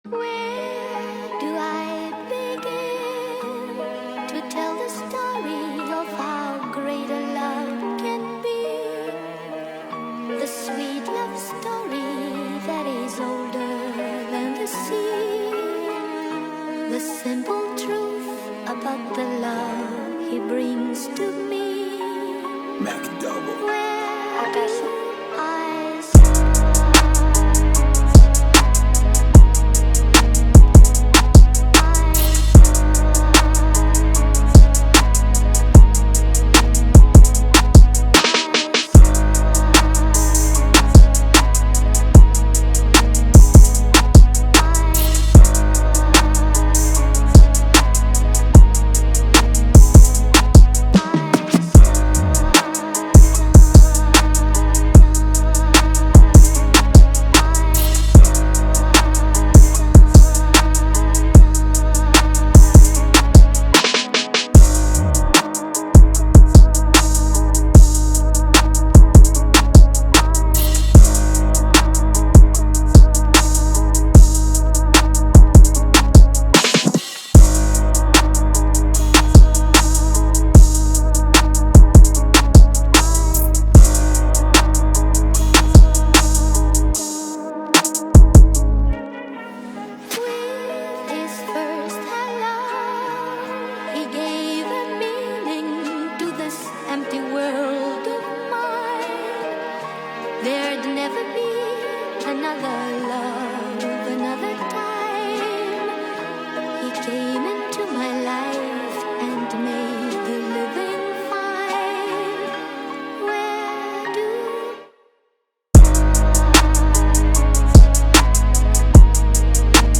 ژانر : ترپ مود : هارد | فست | گنگ
گام : Abm
تمپو : 150